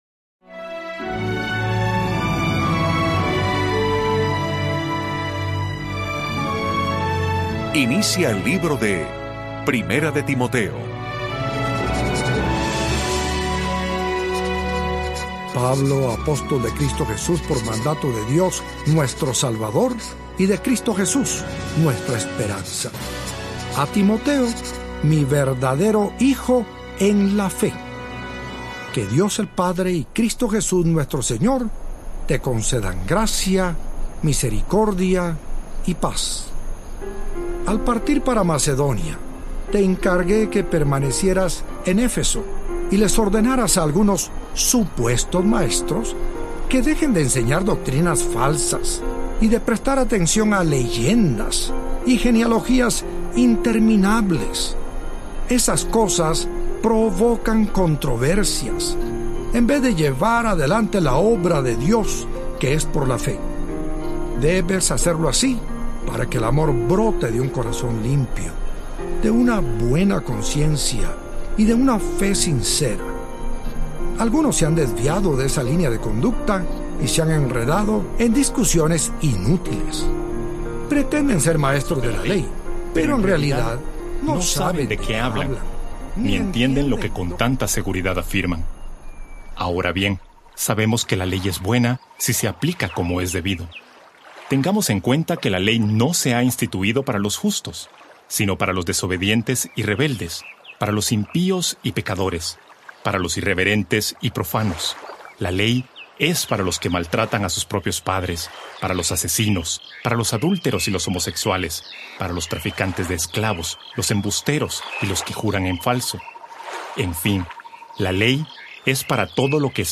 NVI Biblia Experiencia Viva: 1 Tesalonicenses y Filemón Audiobook
Disfruta 1 Tesalonicenses y Filemón en las voces de siervos, adoradores, pastores y maestros de la Biblia cuyos ministerios y acciones han bendecido a la Iglesia del Señor de habla hispana.
Se trata de la belleza, del drama, la ternura y el poder transformador de la Escritura como nunca antes se ha escuchado.Esta histórica producción en audio incluye las voces de más de cincuenta de nuestros mas estimados y reconocidos consiervos de origen hispano.
1.0 Hrs. – Unabridged